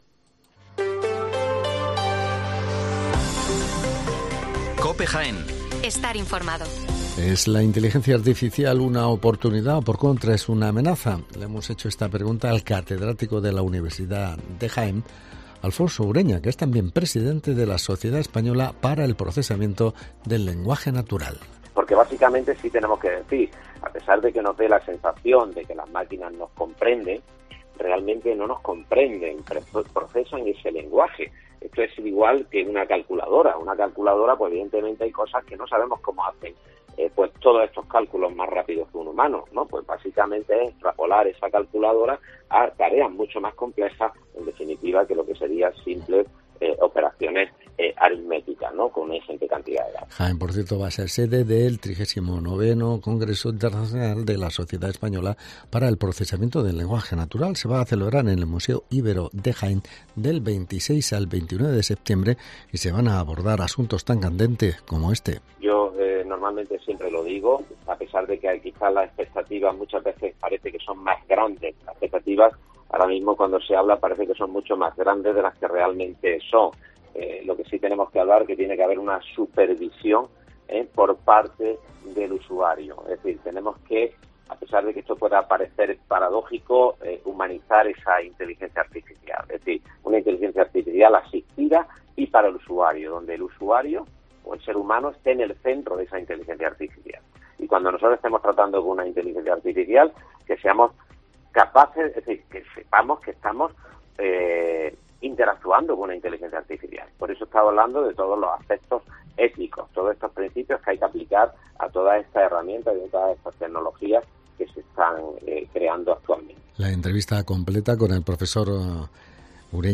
Las noticias locales del 7 de septiembre de 2023